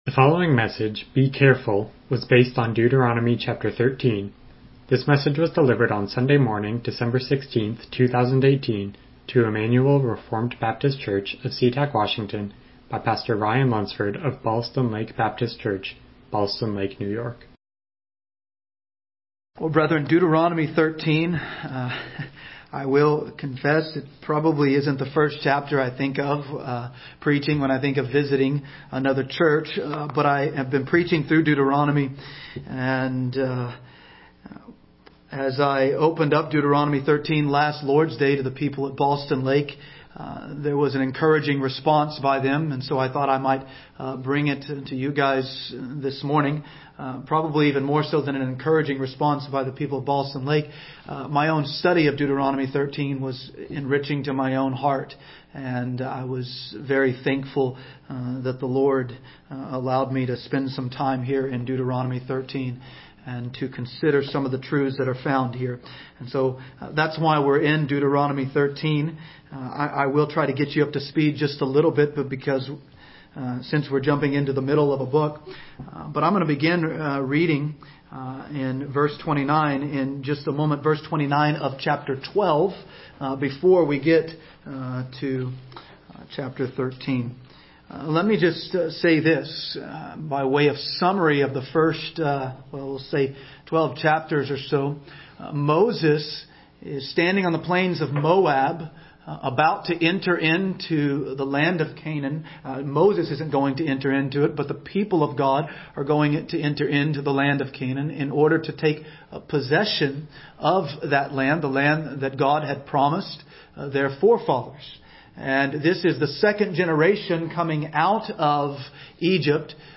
Deuteronomy 13:1-18 Service Type: Morning Worship « Qualifications of Elders